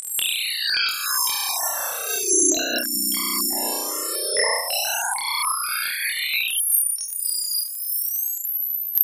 Dabei verlieren sich Infos ab 15kHz.